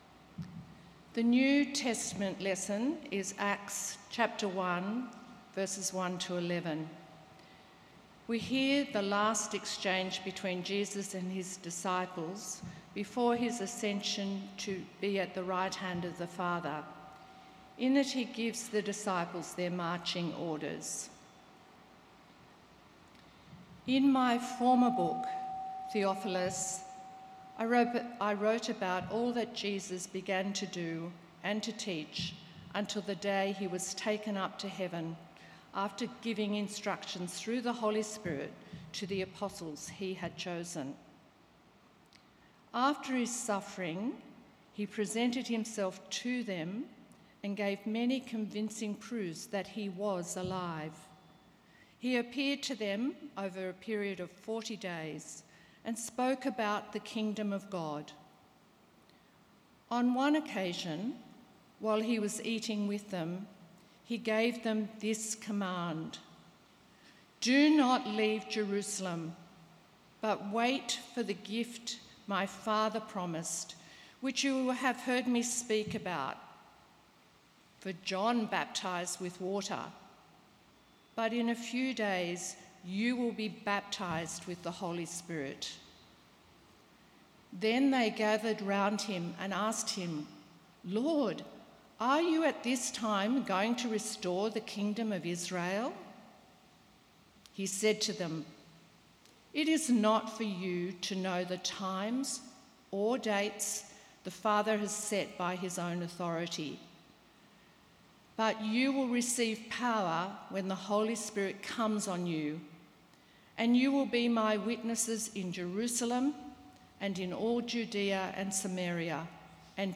Sermon 10th August  2.mp3